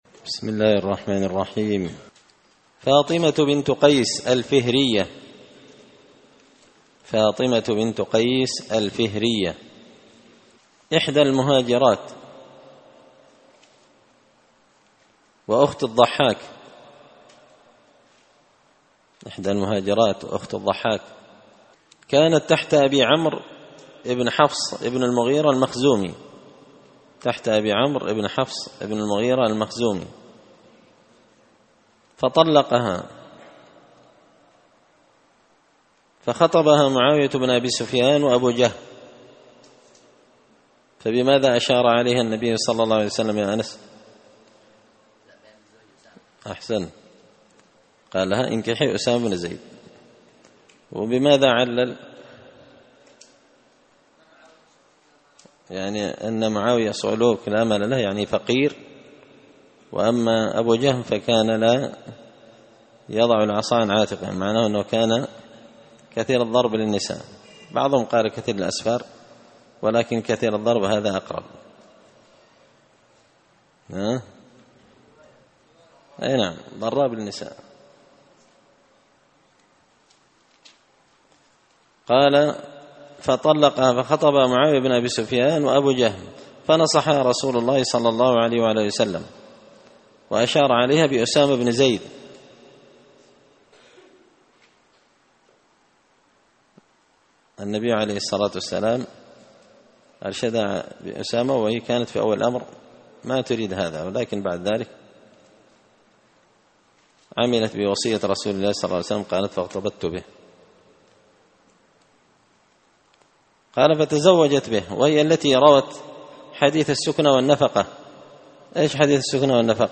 الدرس 105 فاطمة بنت قيس الفهرية
دار الحديث بمسجد الفرقان ـ قشن ـ المهرة ـ اليمن